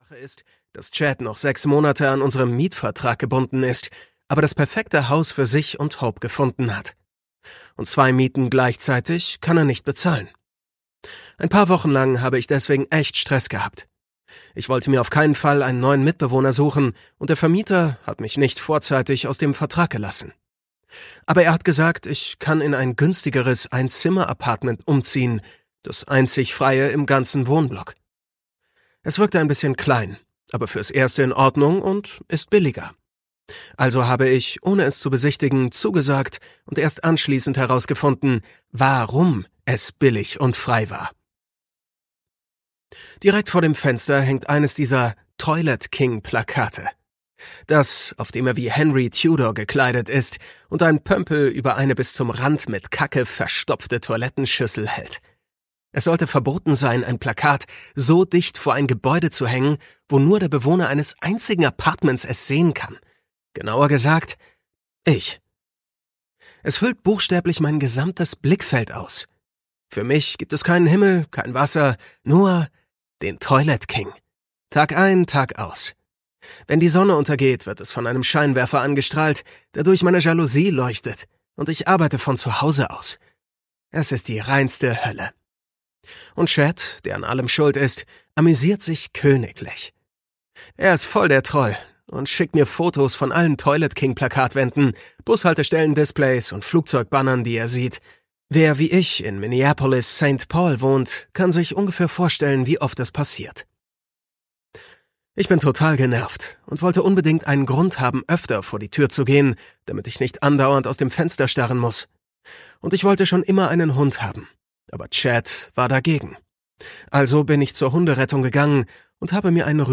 Just for the Summer (DE) audiokniha
Ukázka z knihy